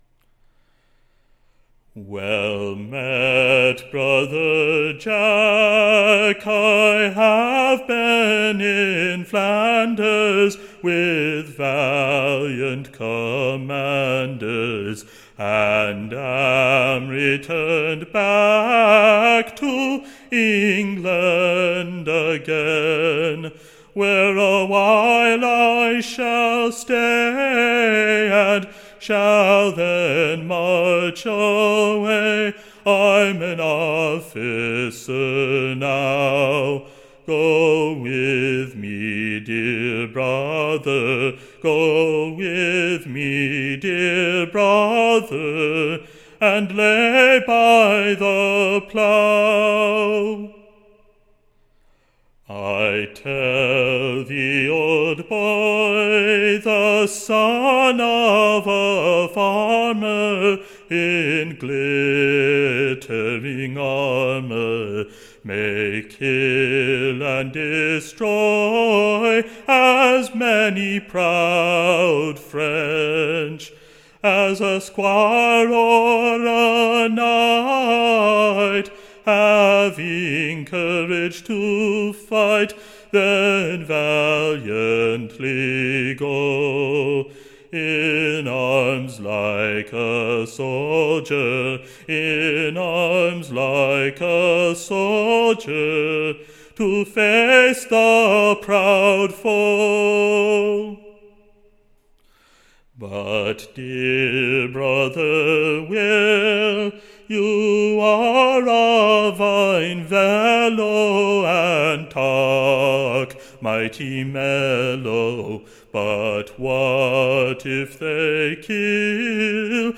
Ballad
Tune Imprint Tune of Mary live long Standard Tune Title Let Mary Live Long Media Listen 00 : 00 | 9 : 30 Download r2.169.mp3 (Right click, Save As)